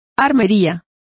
Complete with pronunciation of the translation of arsenals.